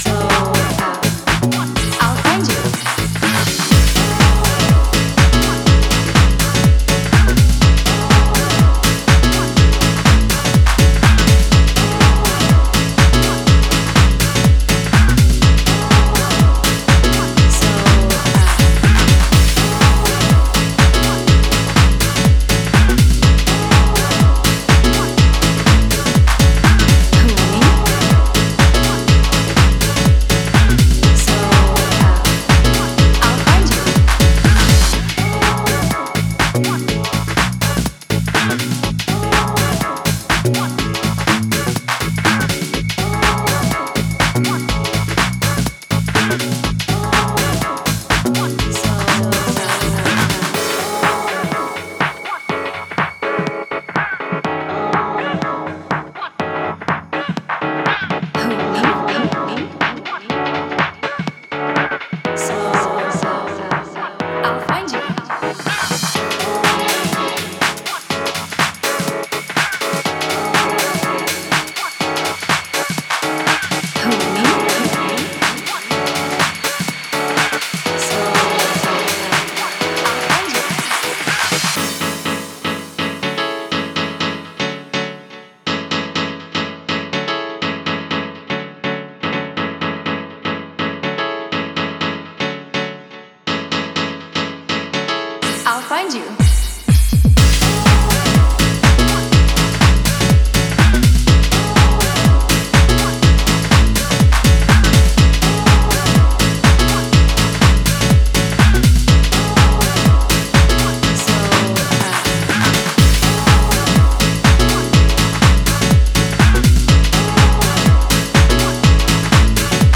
Styl: Disco, House